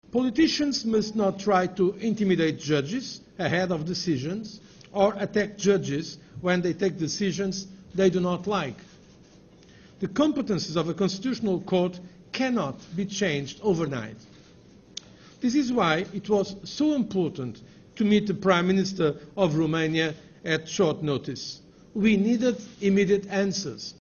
Jose Manuel Barroso la conferința de presă de la Bruxelles